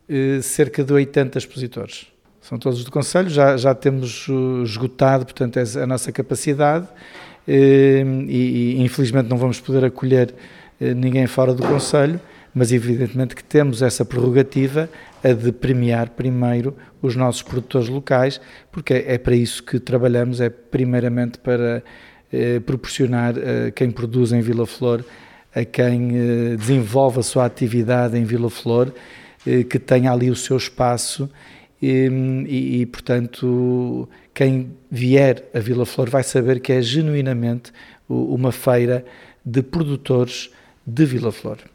O presidente da Câmara Municipal de Vila Flor, Pedro Lima, sublinha que o evento pretende valorizar o território, a cultura e os produtos locais: